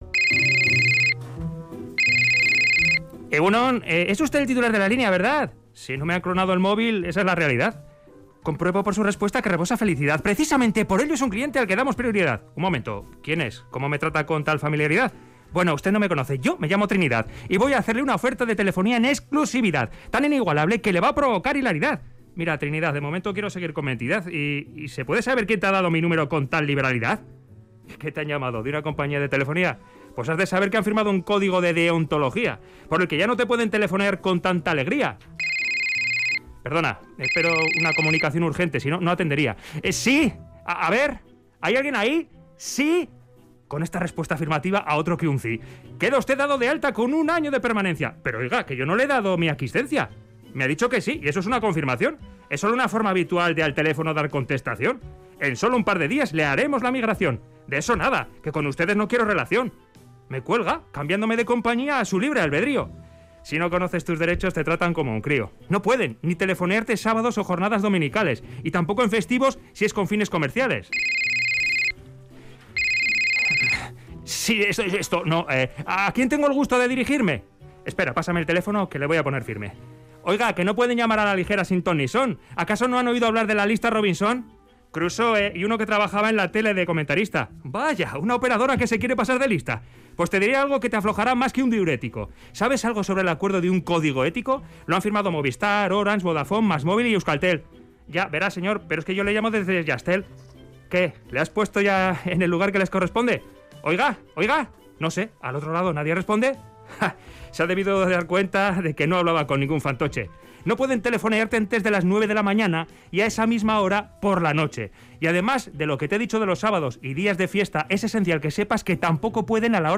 Crónica en verso: Coto al acoso de las llamadas de telefonía